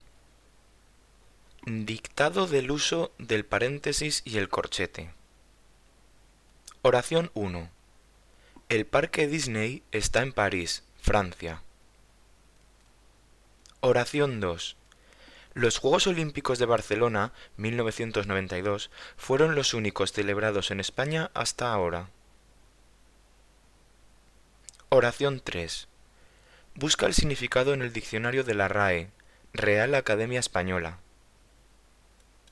Dictado sobre el uso del paréntesis y el corchete (CC BY-SA)
Dictado_Parentesis.mp3